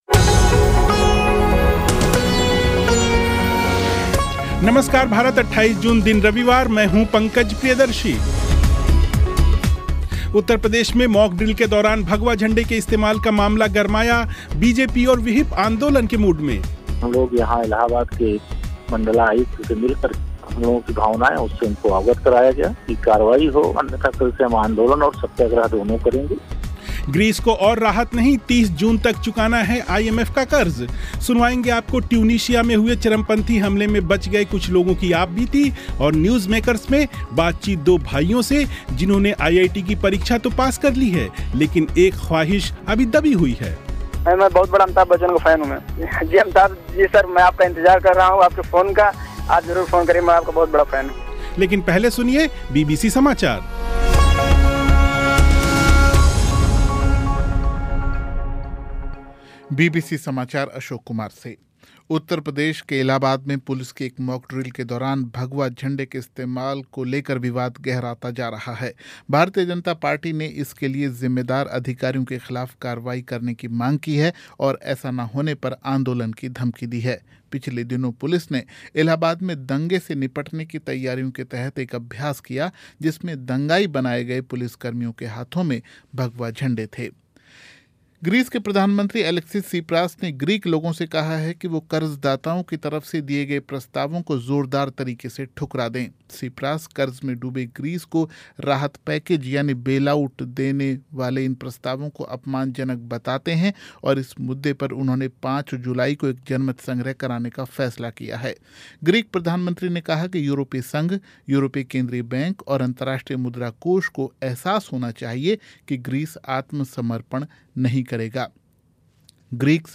न्यूज़मेकर्स में बातचीत दो भाइयों से, जिन्होंने आईआईटी की परीक्षा तो पास कर ली है, लेकिन एक ख़्वाहिश अभी दबी हुई है.